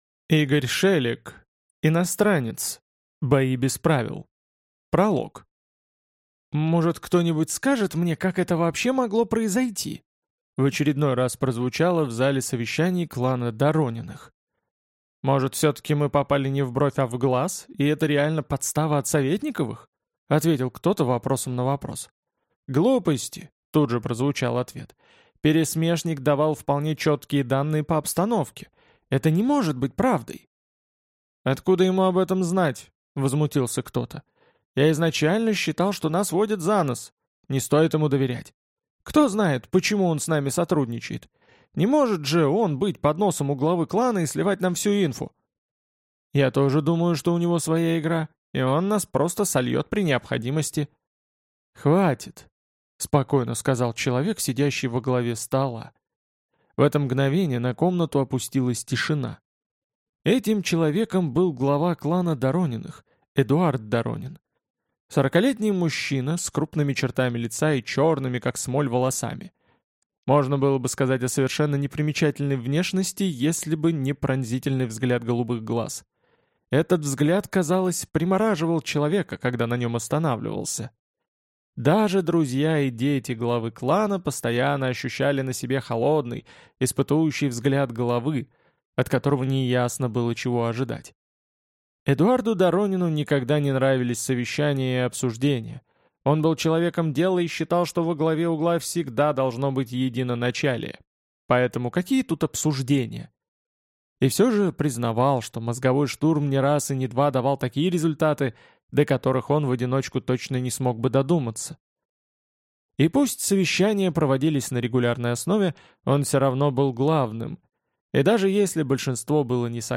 Аудиокнига Иностранец. Бои без правил | Библиотека аудиокниг